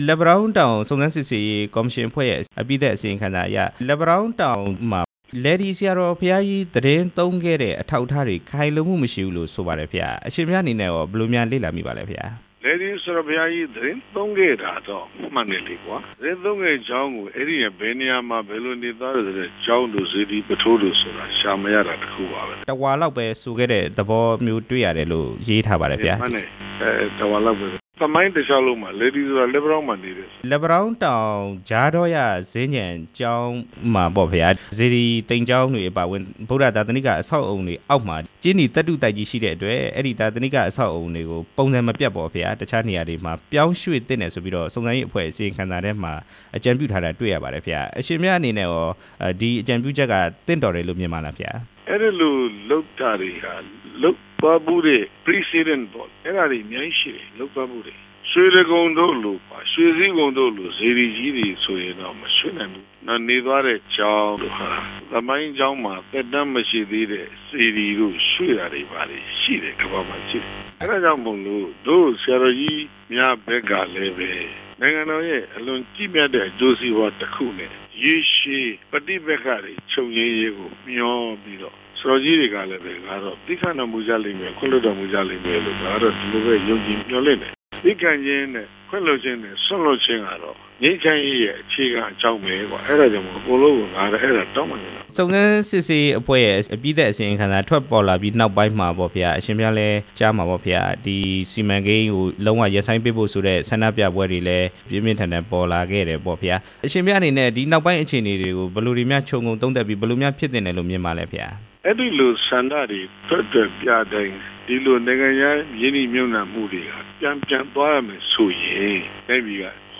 သီတဂူဆရာတော် ဒေါက်တာ အရှင်ဉာဏိဿရ မိန့်ကြားချက်